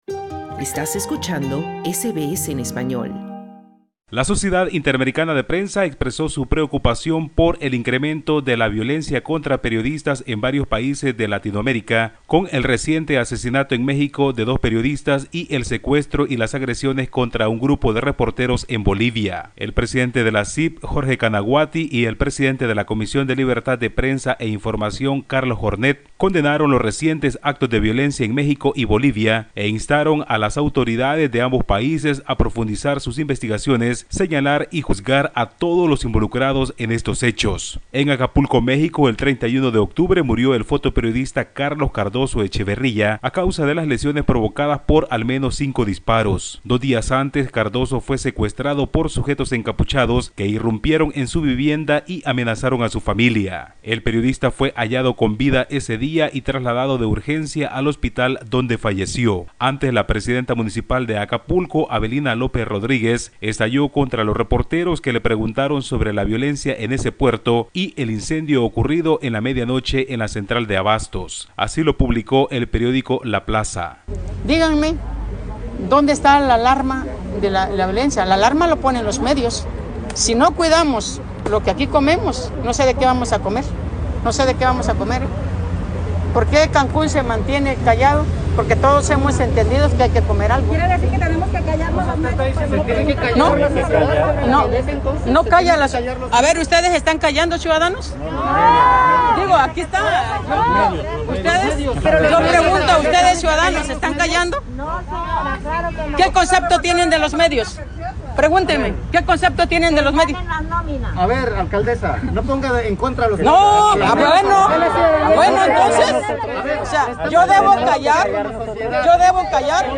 Tras el reciente asesinato en México de un comunicador y el secuestro y agresiones contra un grupo de reporteros en Bolivia, la Sociedad Interamericana de Prensa y la Comisión de Libertad de Prensa e Información expresaron su preocupación por el incremento de la violencia contra periodistas en varios países de Latinoamérica. Escucha el informe del corresponsal de SBS Spanish en América Latina